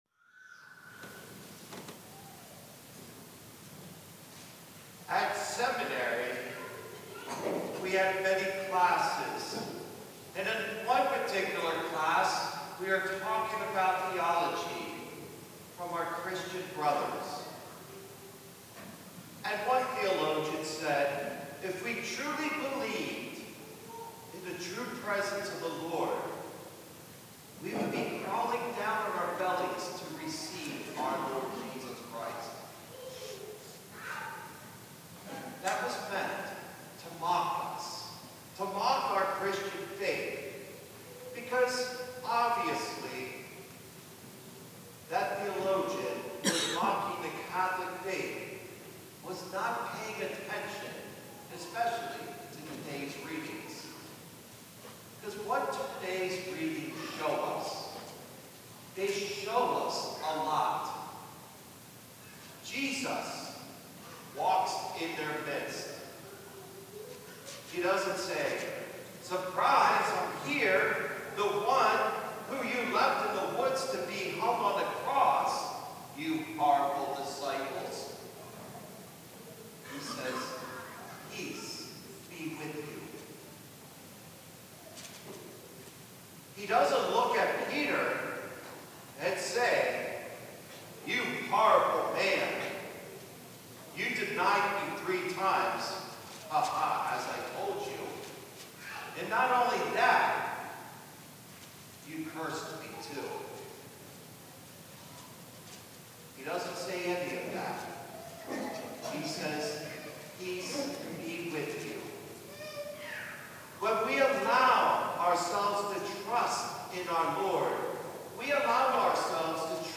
April-27-homily.mp3